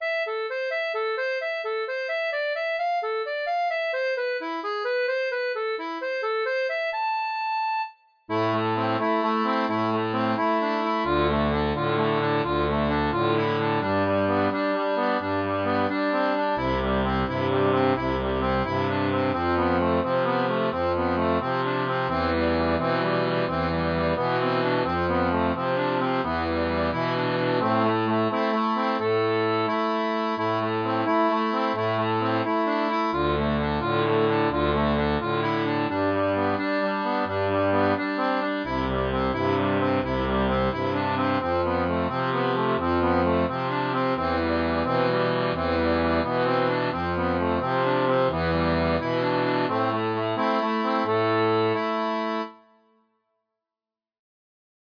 • Une tablature pour diato 2 rangs transposée en La
Chanson française